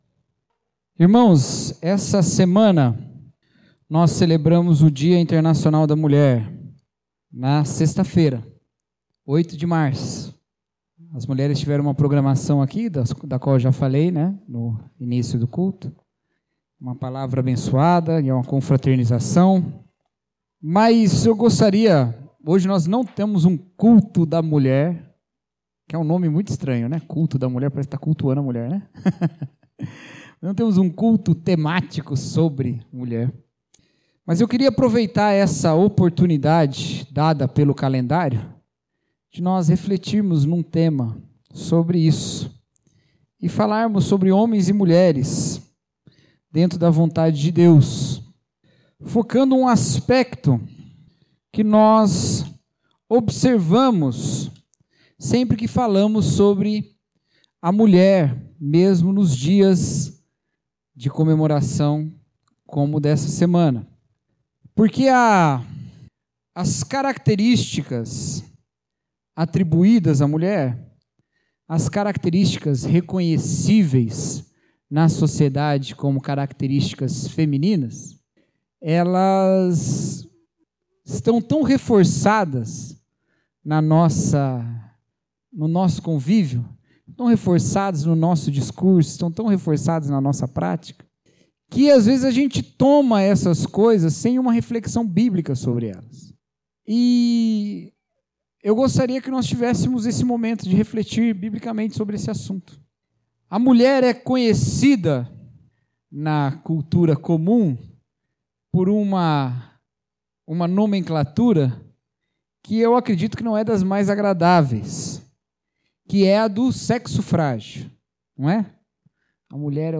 Mensagem: O Pecado do Machismo